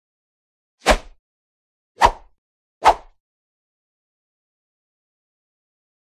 Sword: Swish ( 3x ); Three Quick, Low Pitched Whooshes For Sword Swings. Close Perspective.